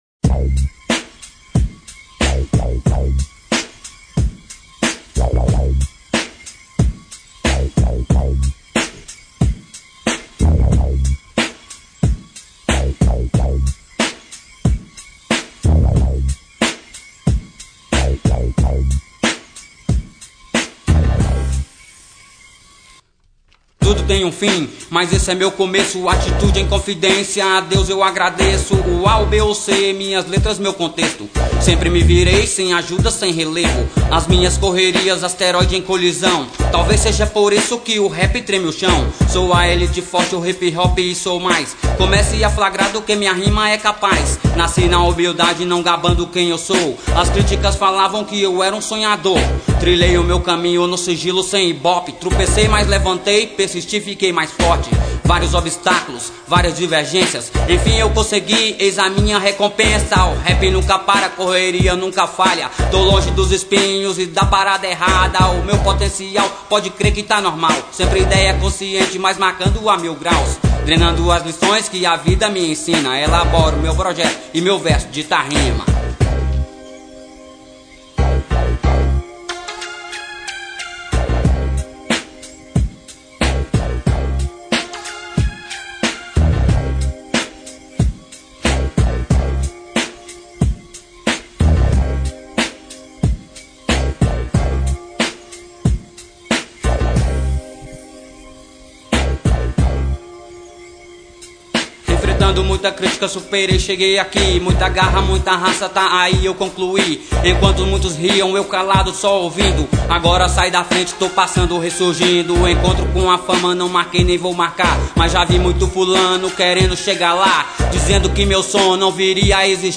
Inst Sample.